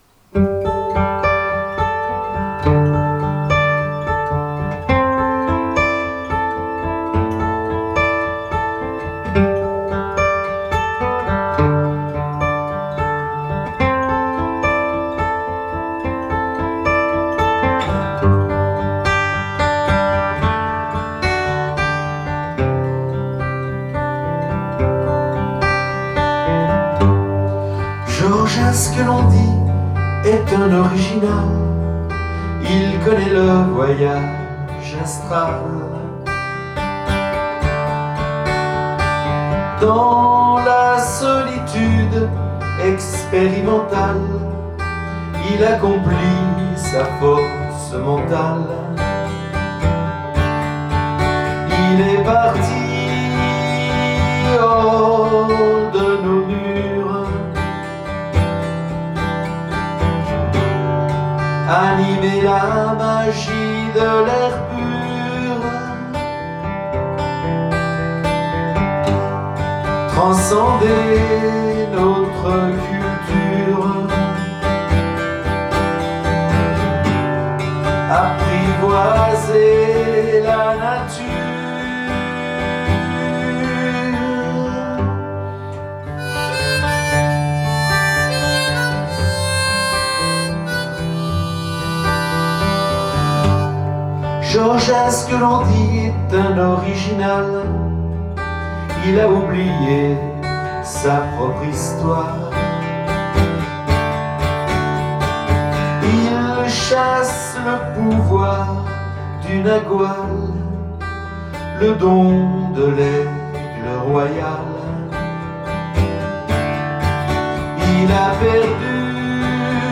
Trial rumba en forêt avec un couple de danseurs